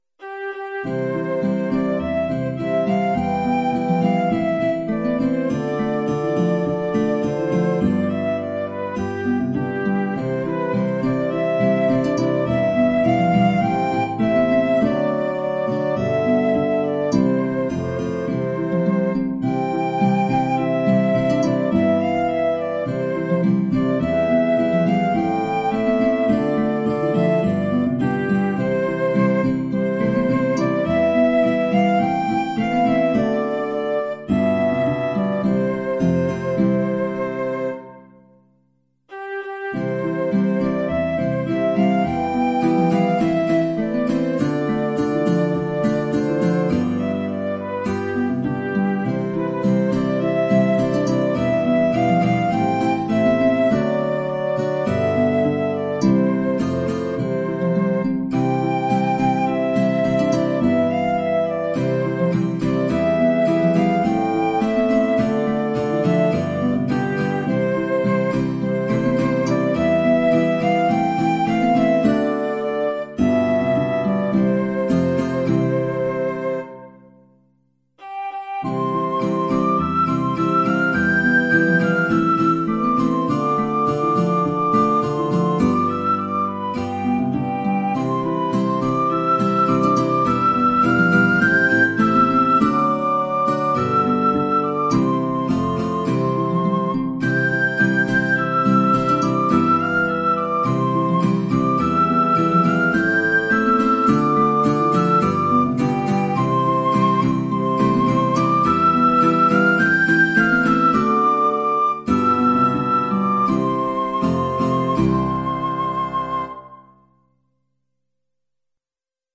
Thanksgiving Greetings
(Recording of “Simple Gifts” graciously provided by Hymns without Words.)
Tis-the-Gift-to-be-Simple-Simple-Gifts-3-Verses-Flute-Basson-Guitars-and-Violin-v2.mp3